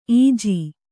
♪ īji